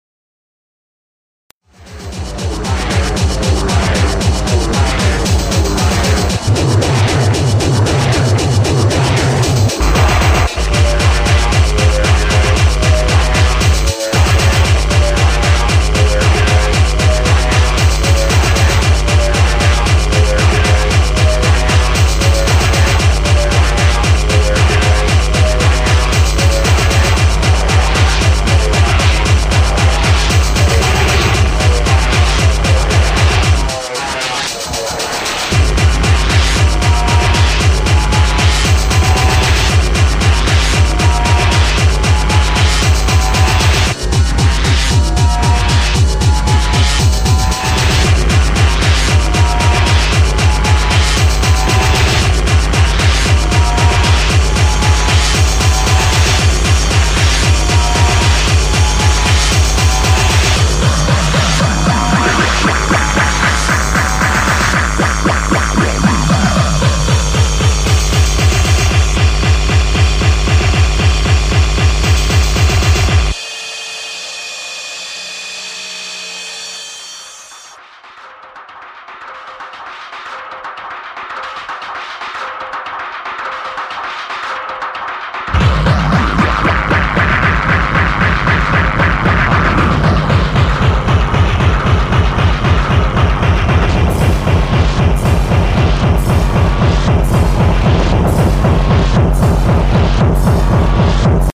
( 230bpm )